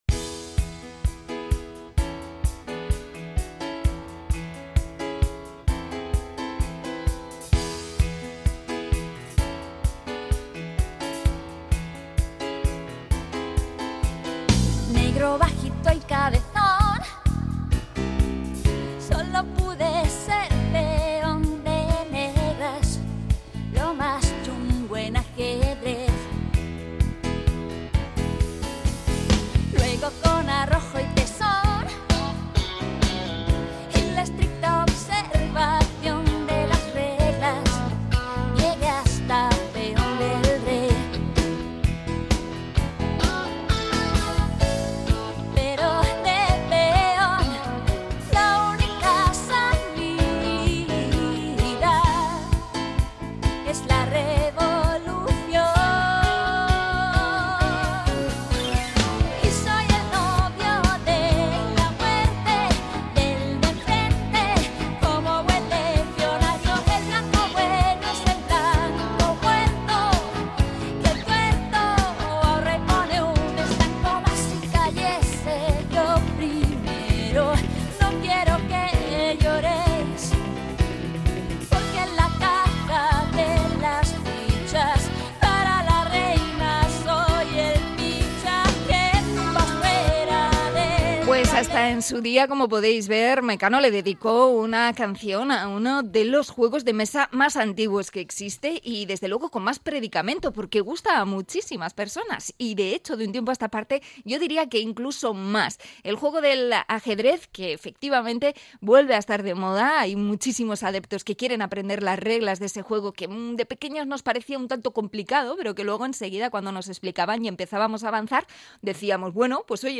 Conectamos desde la txosna Tintigorri con su campeonato de ajedrez